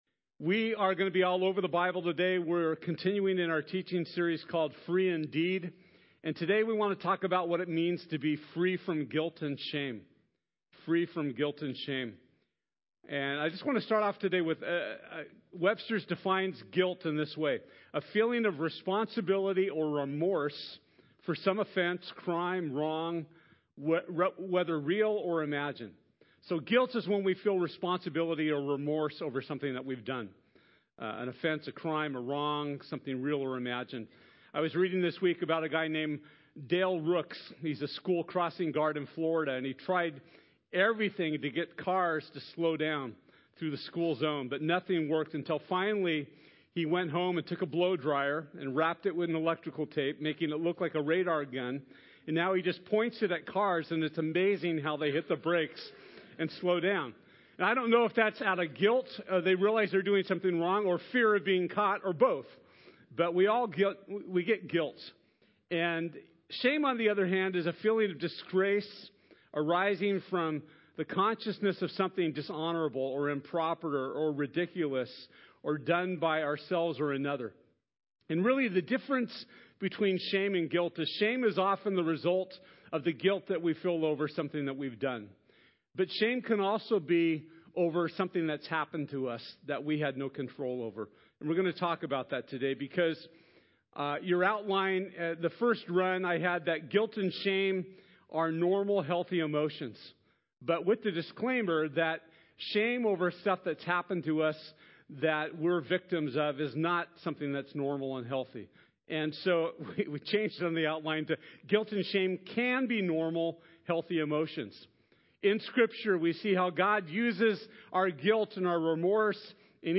Free From Guilt and Shame | Community Bible Church